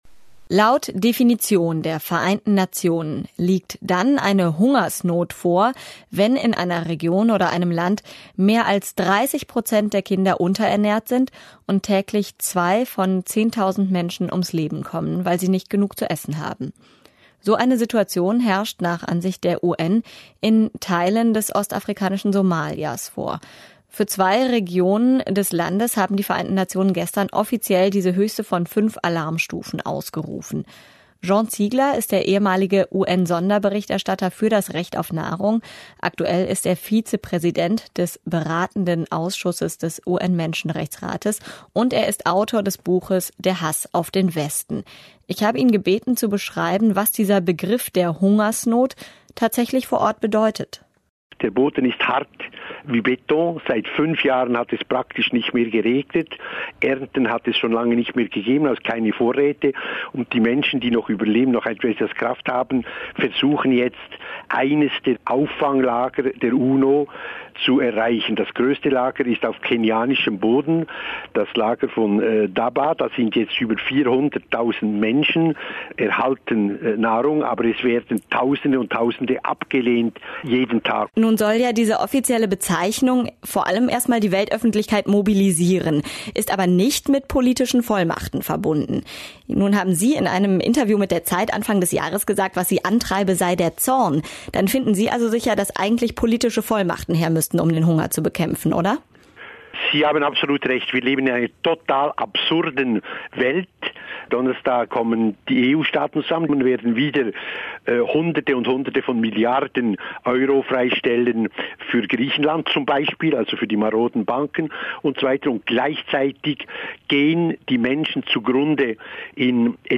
Ein Gespräch mit Jean Ziegler, ehemaliger UN-Sonderberichterstatter für das Recht auf Nahrung und aktueller Vizepräsident des beratenden Ausschusses des UN-Menschenrechtsrates